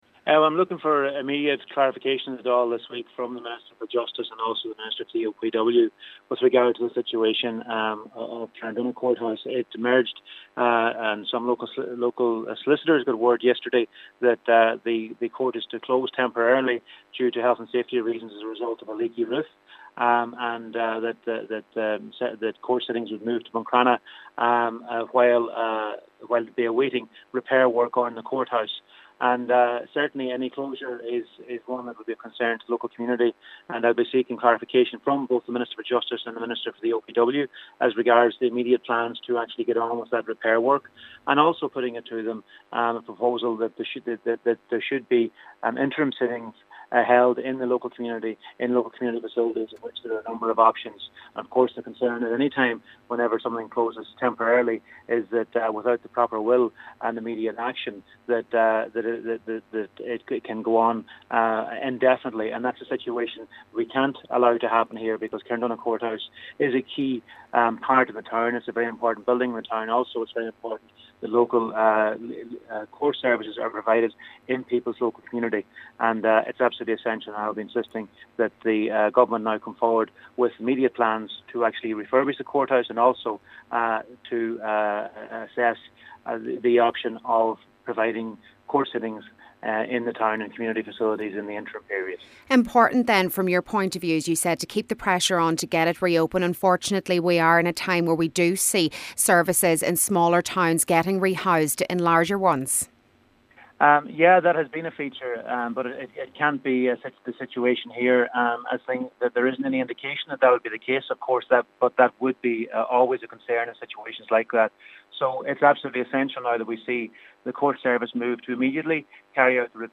Donegal Deputy Charlie McConalogue says it’s imperative that these works are carried out without delay and that this vital service be maintained in the town of Carndonagh: